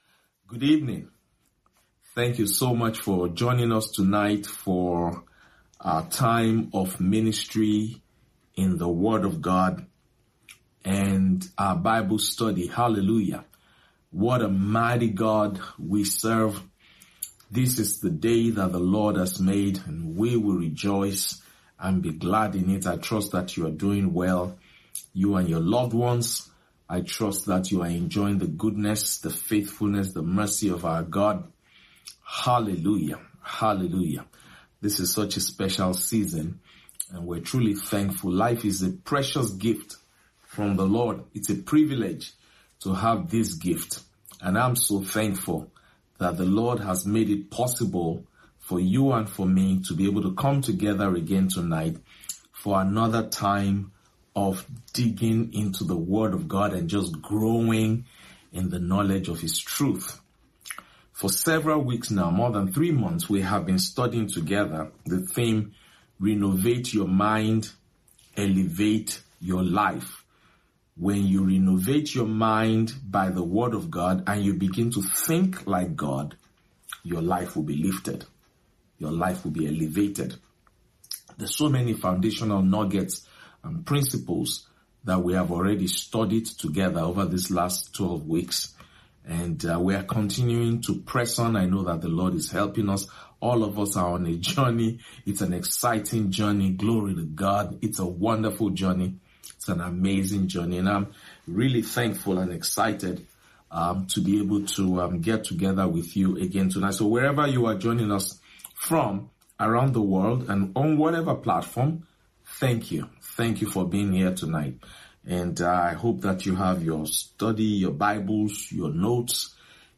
Midweek Service